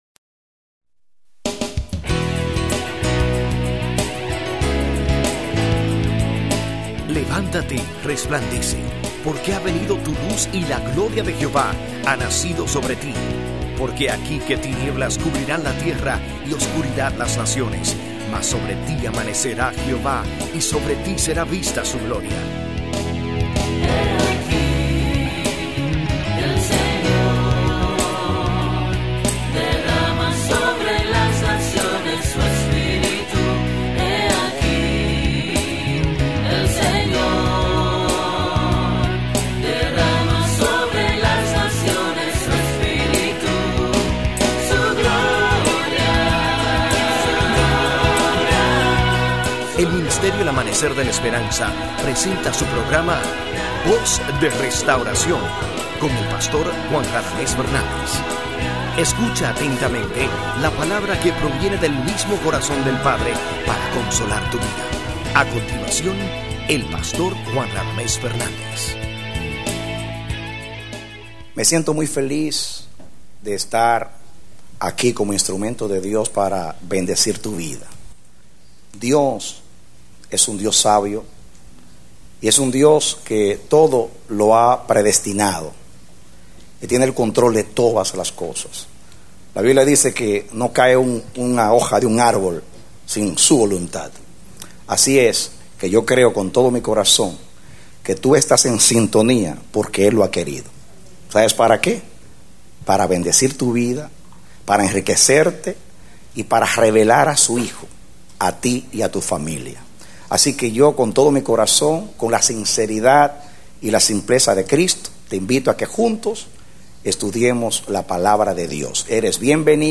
Prédicas – El Amanecer de la Esperanza Ministry